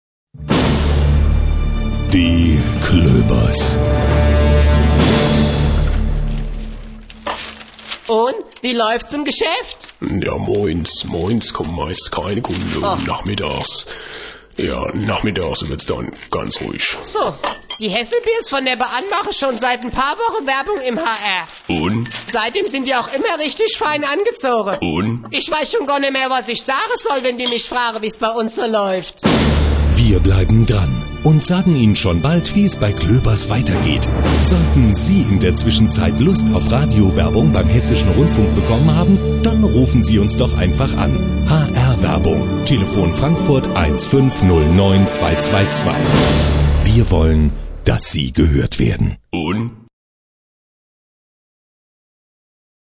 Inspiriert von den ersten Gesprächen mit hessischen Mittelständlern, baute ich die Figur des Herrn Klöber aus, gab ihm eine Stimme, eine wortgewaltige Gattin, erfolgreiche Nachbarn und Sendezeit in den Werbeblöcken.